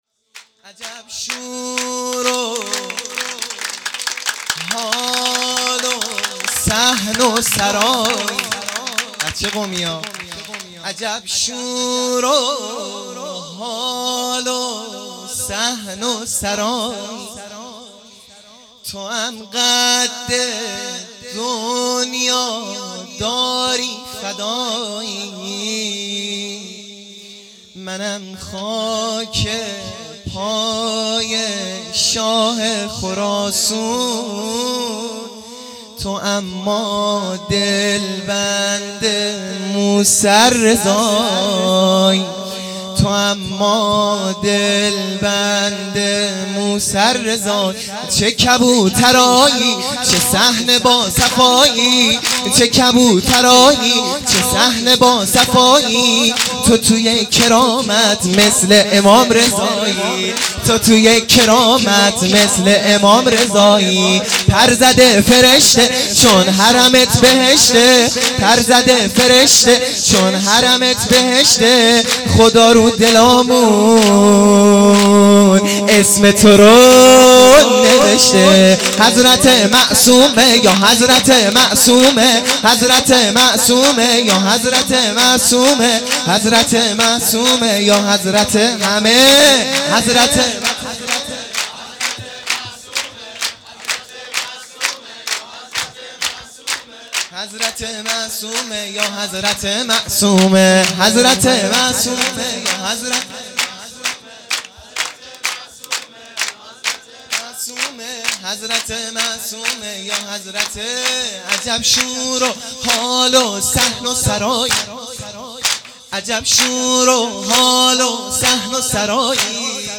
سرود
میلاد حضرت معصومه سلام الله